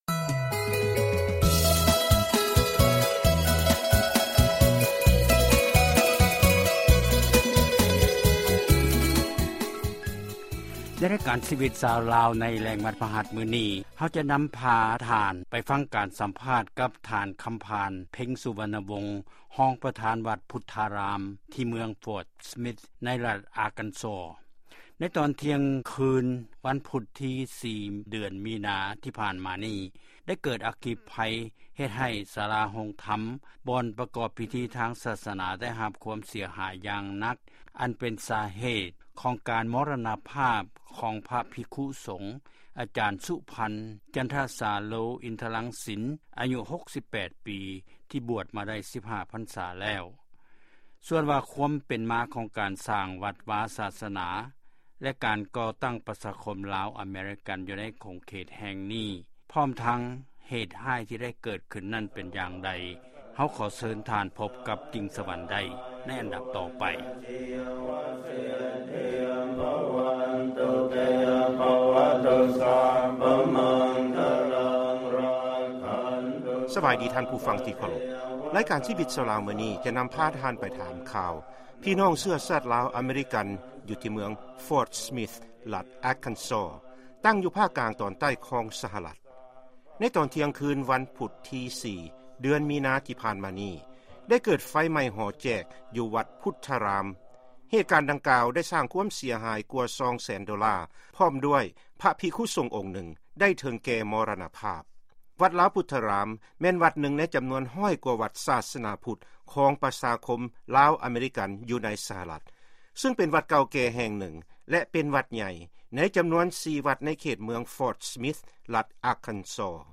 ຟັງການສຳພາດ ກ່ຽວກັບອັກຄີໄພ ຢູ່ວັດລາວພຸດທາຣາມ ເມືອງ Ft. Smith ລັດ Arkansas